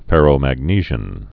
(fĕrō-măg-nēzhən)